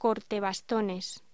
Locución: Corte bastones
voz